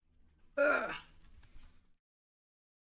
标签： 警察 语音 弗利
声道立体声